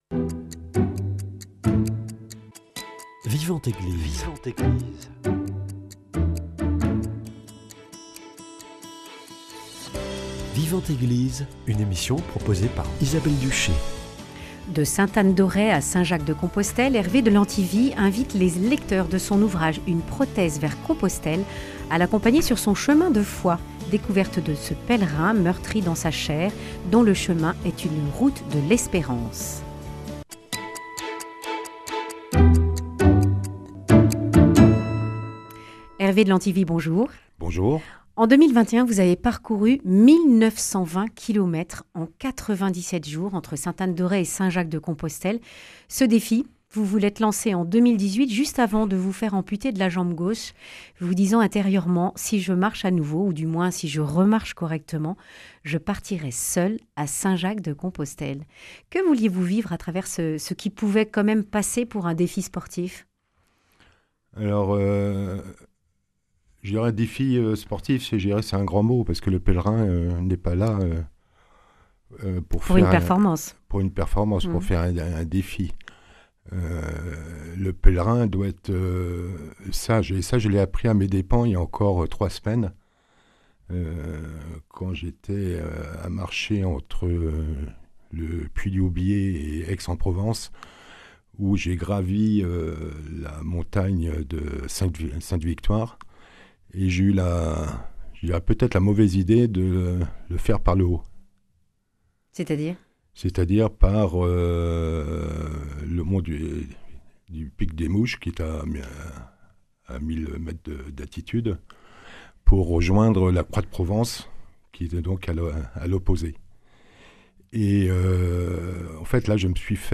Un pèlerinage décapant et salvateur. Témoignage.